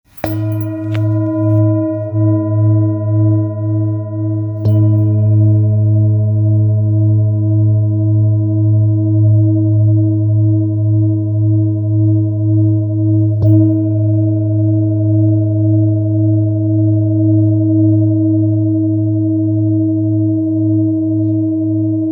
Singing Bowl, Buddhist Hand Beaten, with Fine Etching Carving, Select Accessories, 27 by 27 cm,
Handmade Singing Bowls-31784
Material Seven Bronze Metal